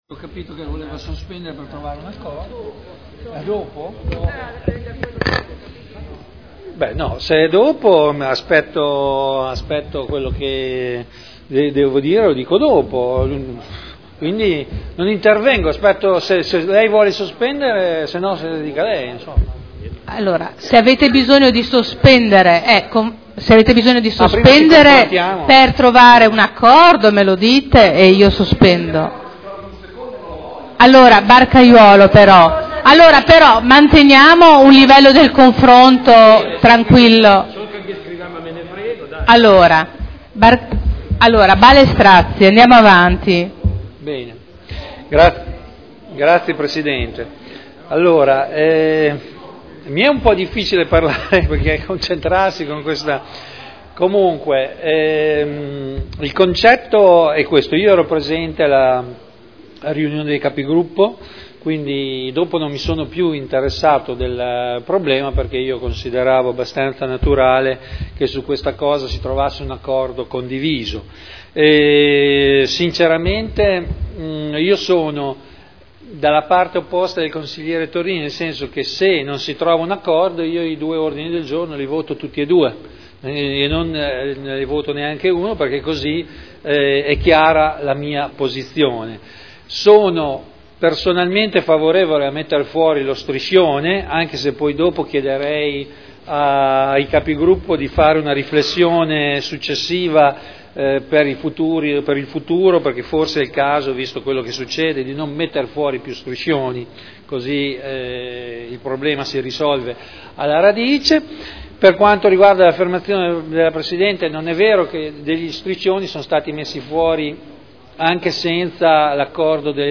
Vittorio Ballestrazzi — Sito Audio Consiglio Comunale